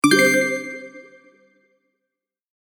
システム決定音_7.mp3